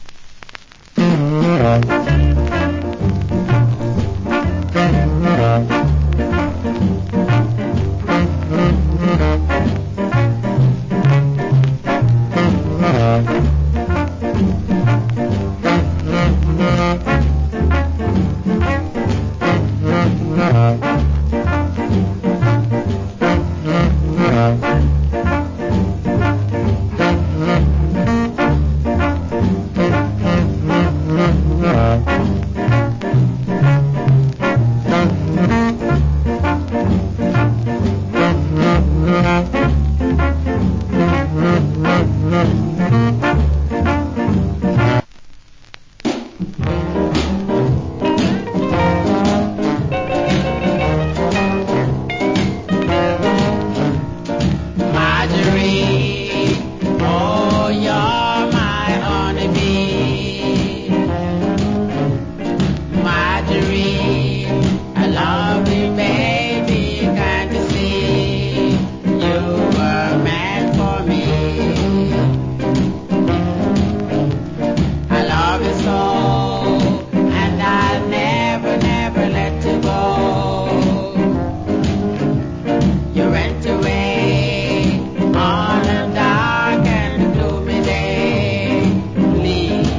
Cool Inst.